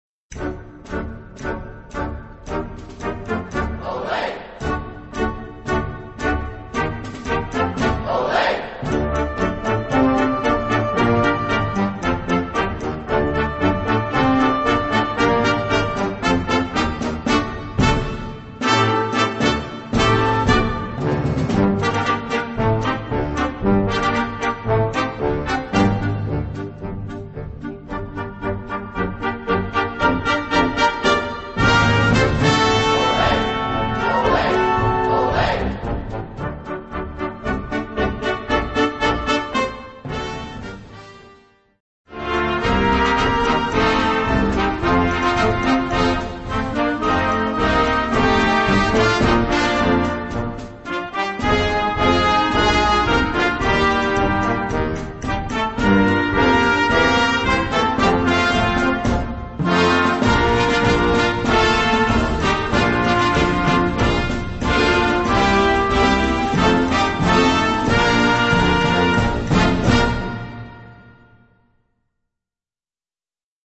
Gattung: Jugend
Besetzung: Blasorchester